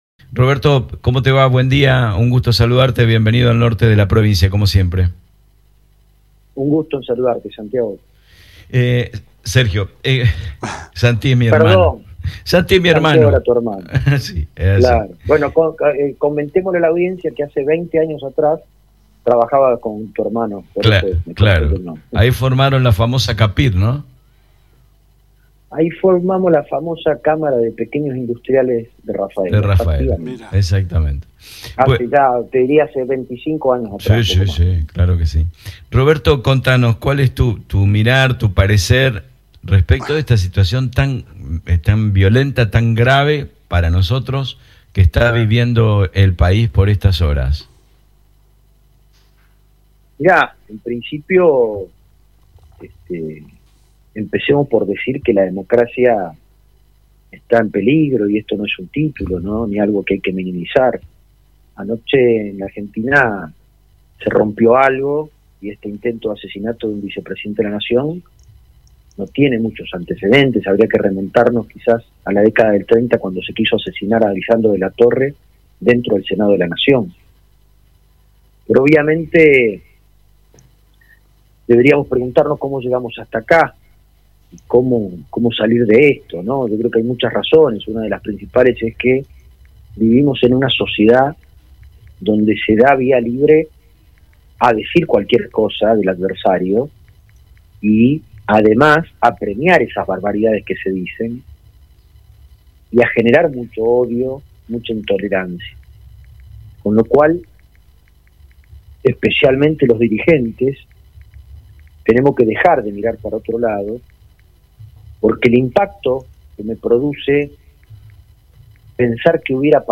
En entrevista con Avellaneda24, Roberto Mirabella dialogó acerca del atentado contra la vicepresidenta de la nación, Cristina Fernández de Kirchner ocurrido anoche en inmediaciones de su domicilio.